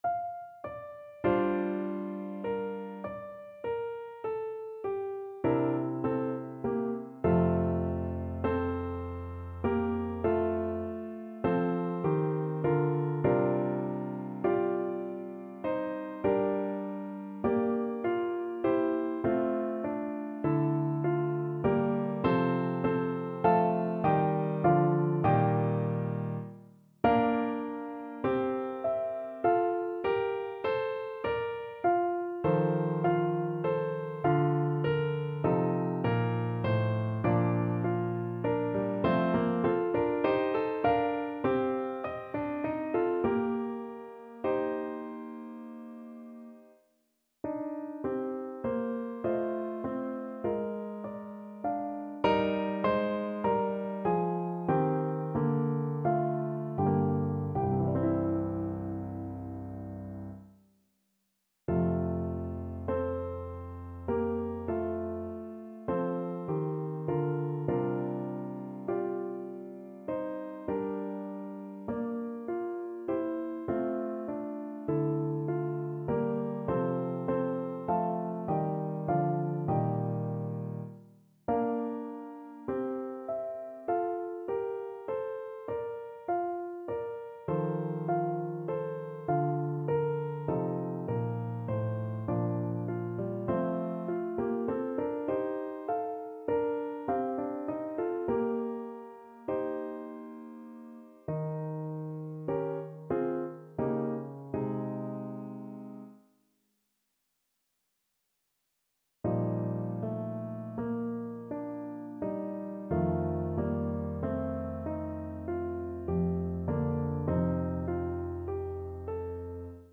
5/4 (View more 5/4 Music)
Classical (View more Classical French Horn Music)